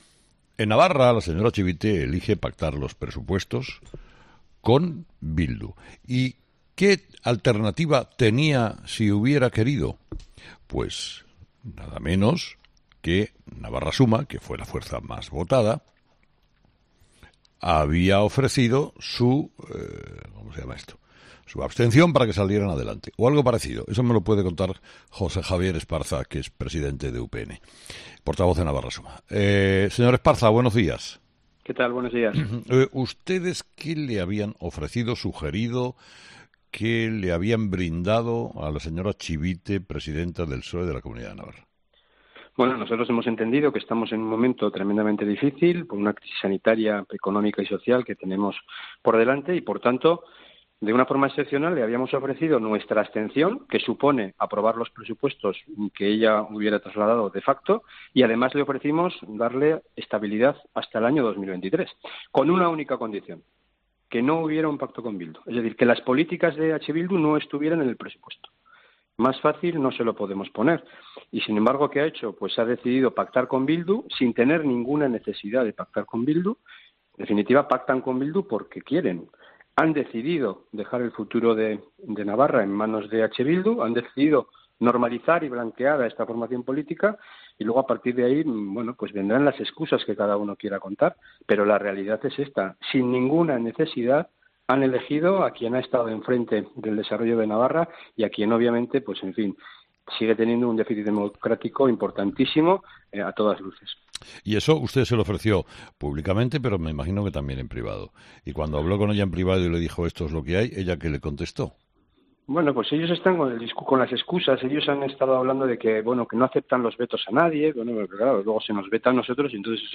El presidente de Navarra Suma, José Javier Esparza, ha explicado en Herrera en COPE que ofreció su abstención a la presidenta del Gobierno de Navarra, María Chivite, para evitar que dependiese de EH Bildu para aprobar los presupuestos de la Comunidad.